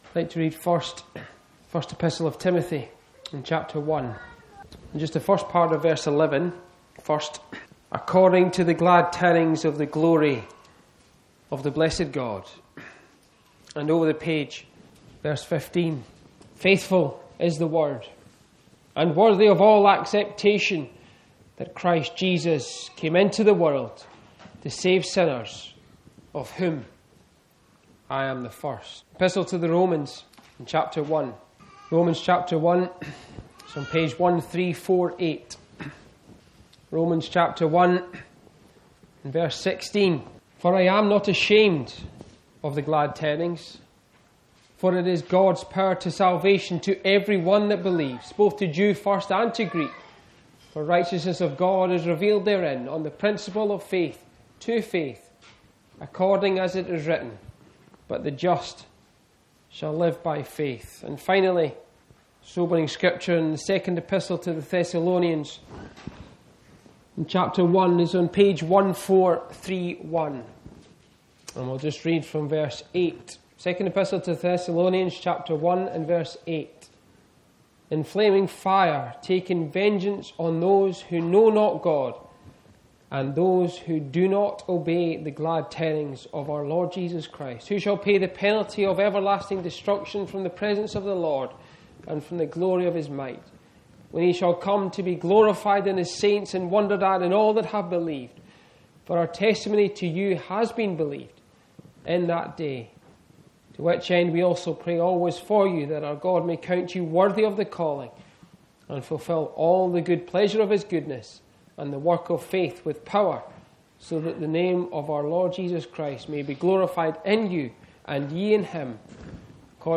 The Glory of the Glad Tidings reveals the beauty and power of what God has done through Jesus Christ. This uplifting preaching highlights the joy, hope, and eternal life found in the message of the cross.